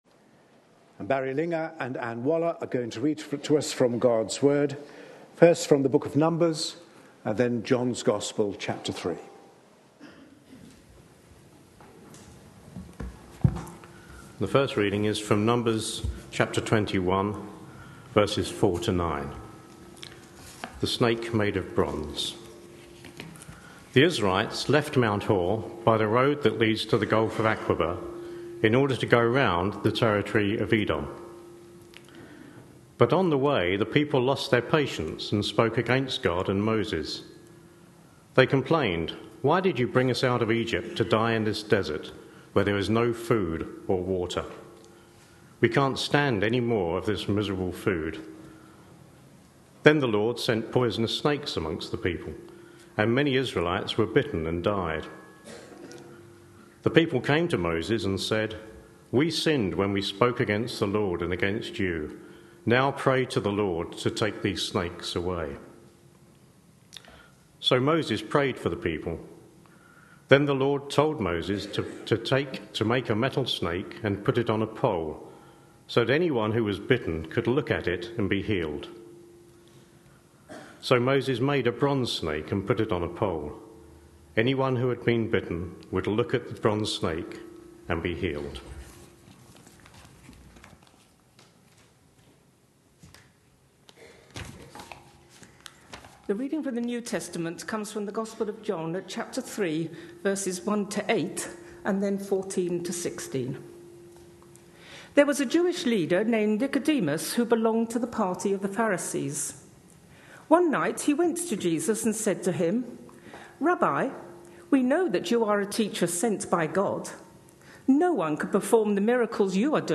A sermon preached on 23rd February, 2014.